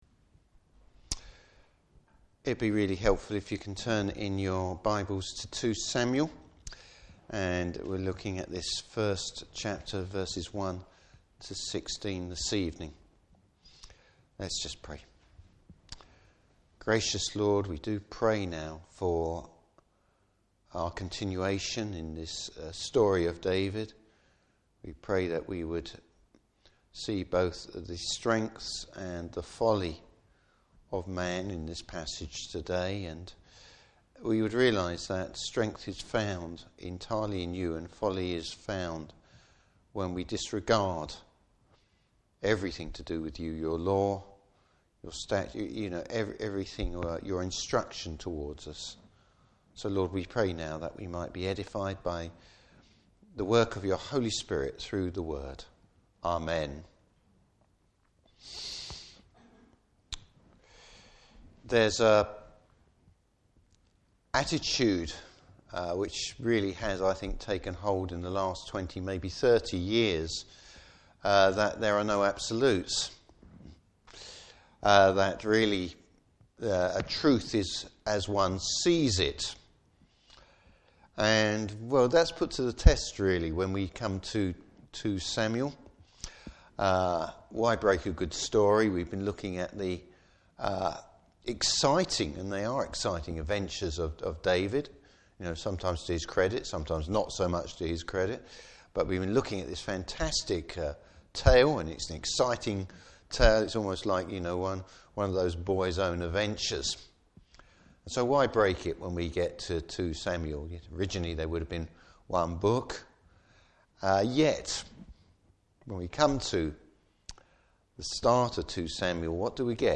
Service Type: Evening Service A lie with tragic consequences!